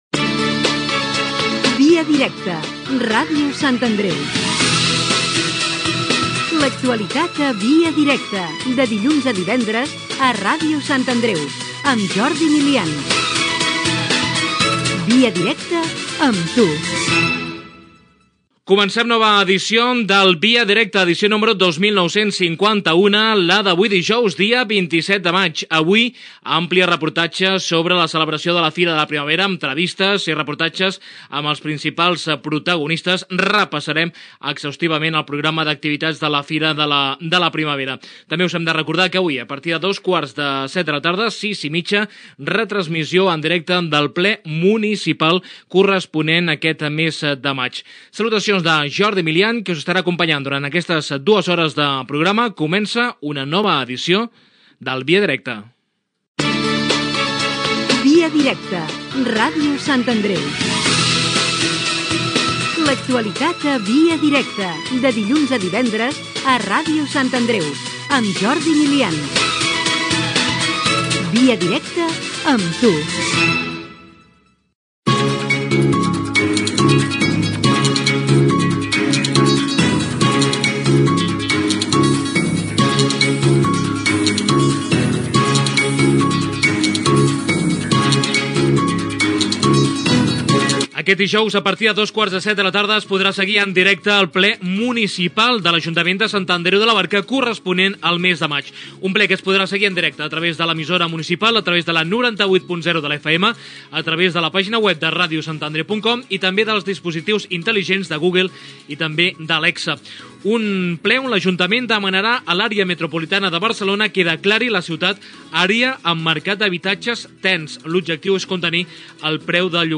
Careta del programa, sumari i identificació del programa.
Informatiu